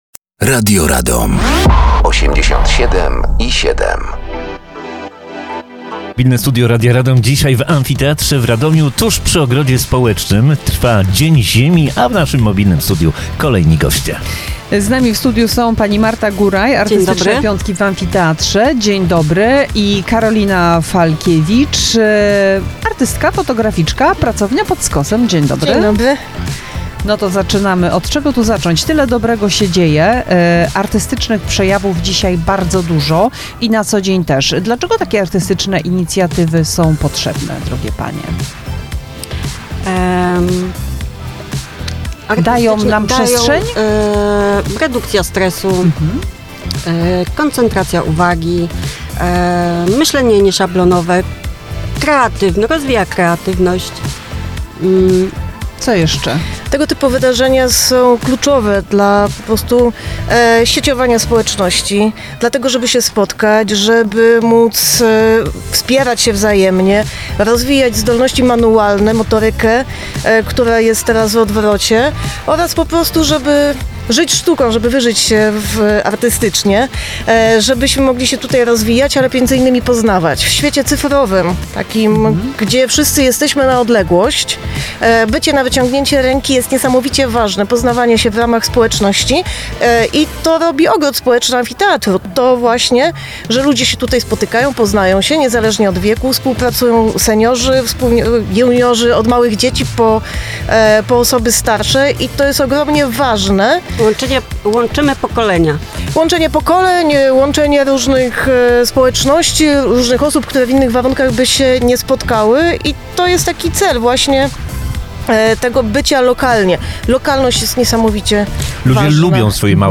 Mobilne Studio Radia Radom na Dniach Ziemi w Ogrodzie Społecznym w Amfiteatrze w Radomiu.